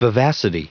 Prononciation du mot : vivacity